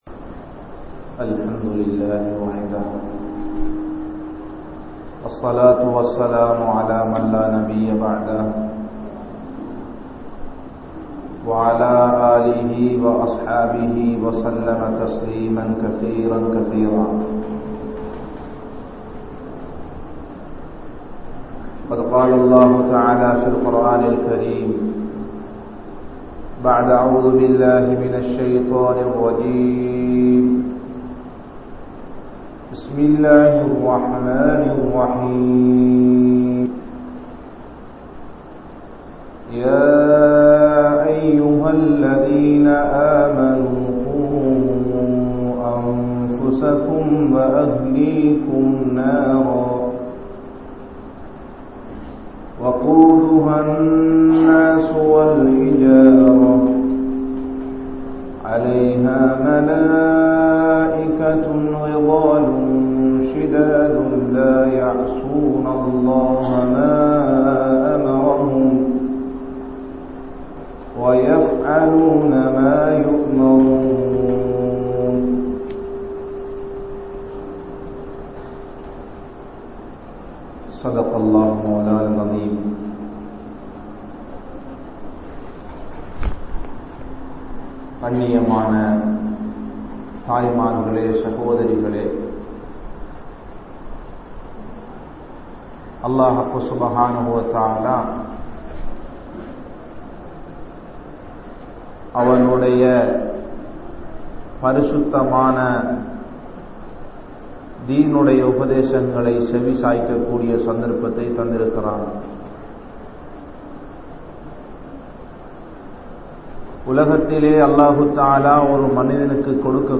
Pillaihalai Valarpathan Noakkam Enna? (பிள்ளைகளை வளர்ப்பதன் நோக்கம் என்ன?) | Audio Bayans | All Ceylon Muslim Youth Community | Addalaichenai
Masjithur Ravaha